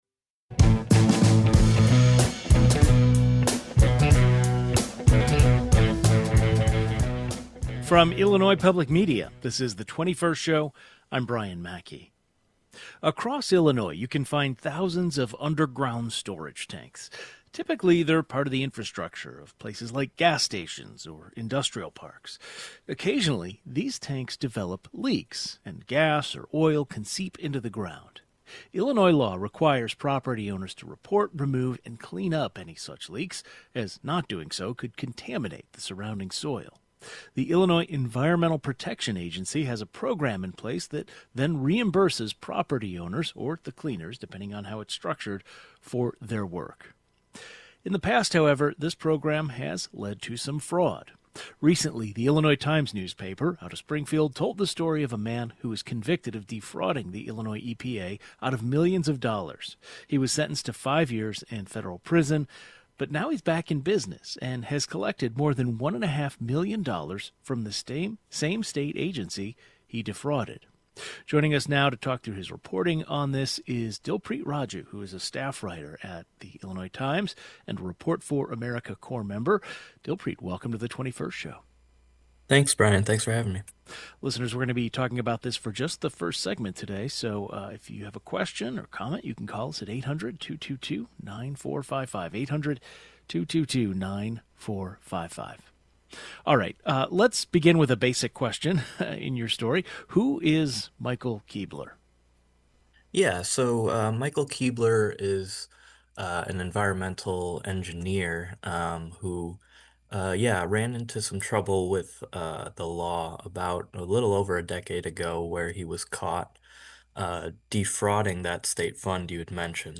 A reporter who investigated the payments joins the program.